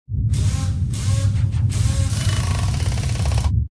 repair4.ogg